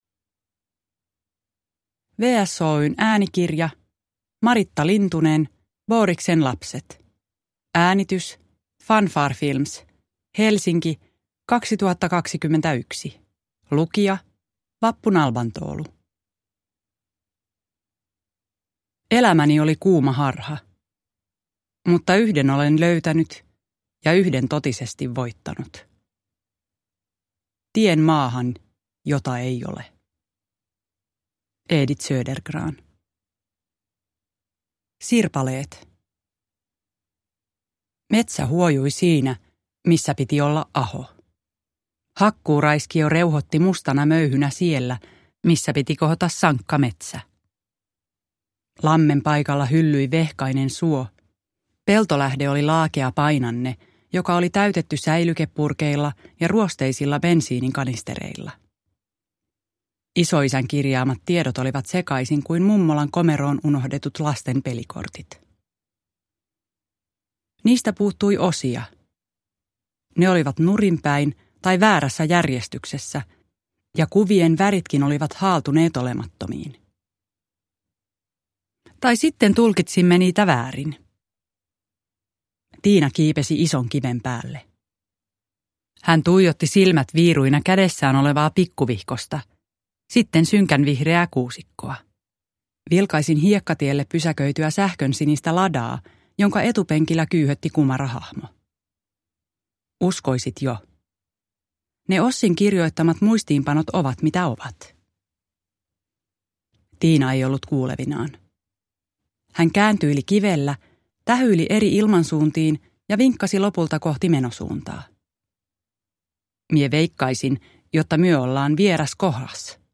Boriksen lapset – Ljudbok – Laddas ner